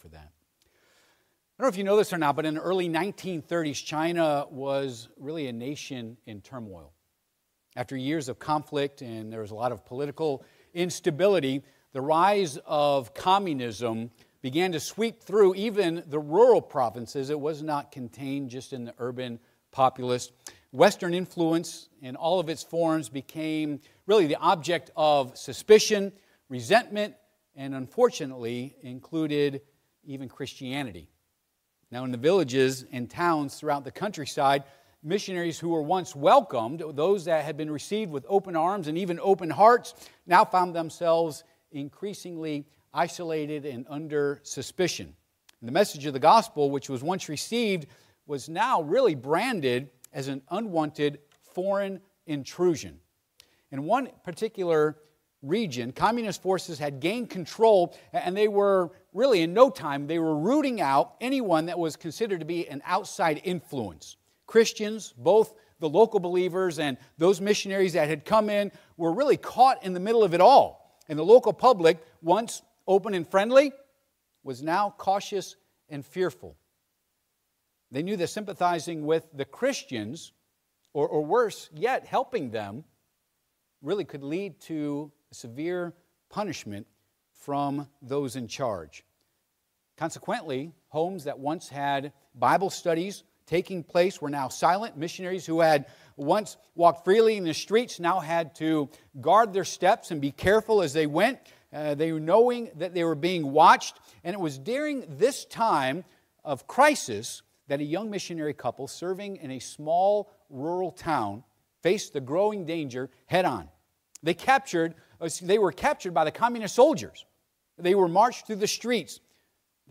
Passage: Acts 8:1- Service Type: Sunday AM